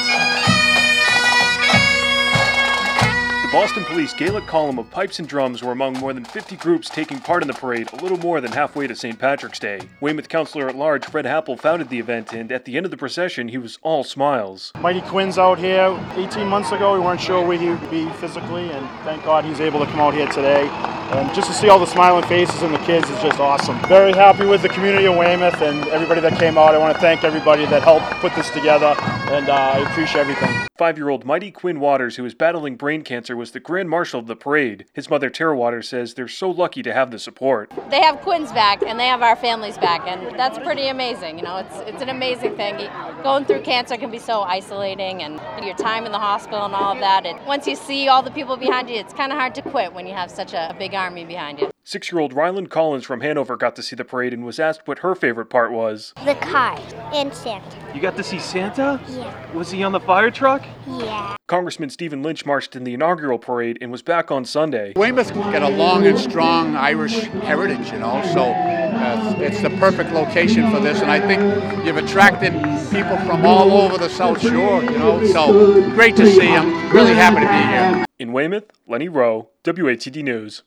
files this report